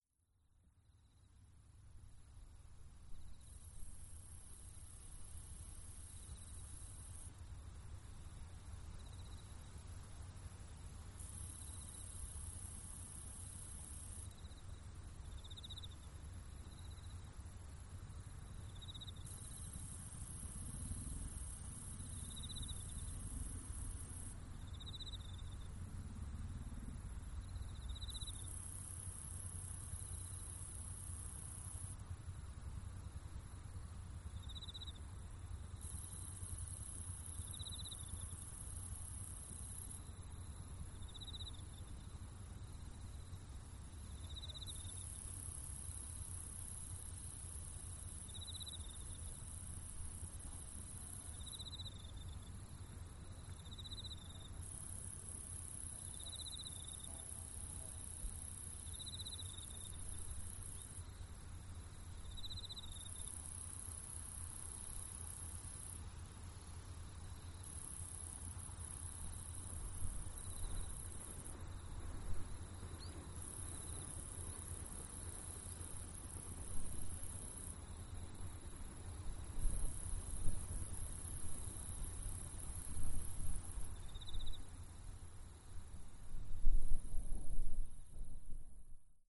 ポッドキャストにもアップしているウスイロササキリの鳴き声を使って、レートを変えてMP3圧縮しスペクトログラムで比較してみた。
ビットレート64kbpsでは11kHz付近でローパスフィルターがかかっているらしい。
▲MP3圧縮　64kbps 48kHz　ローパスフィルターは11kHz付近にかけてあるようだ